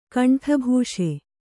♪ kaṇṭhabhūṣe